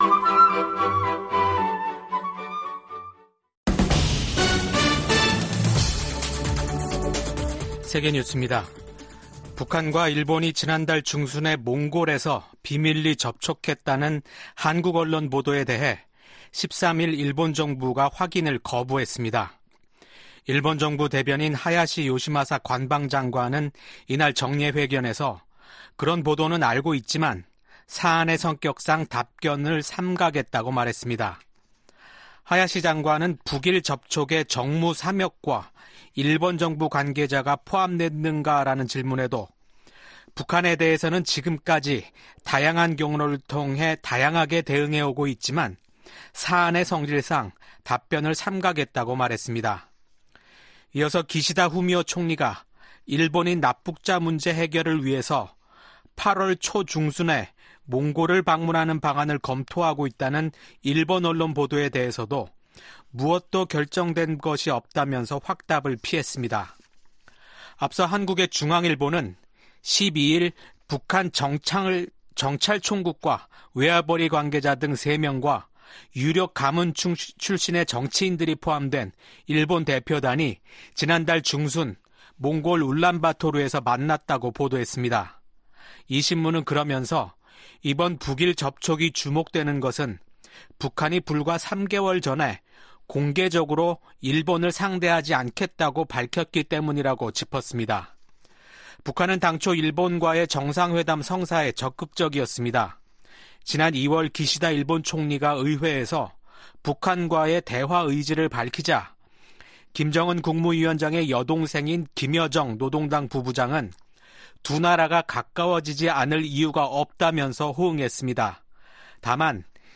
VOA 한국어 아침 뉴스 프로그램 '워싱턴 뉴스 광장' 2024년 6월 14일 방송입니다. 유엔 안보리에서 열린 북한 인권공개 회의에서 미국과 한국, 일본 등은 북한 인권 유린이 불법적인 무기 개발과 밀접한 관계에 있다고 지적했습니다. 미한일 등 50여개국과 유럽연합(EU)이 뉴욕 유엔본부에서 개선될 조짐이 없는 북한의 인권 상황에 대해 우려를 표명했습니다.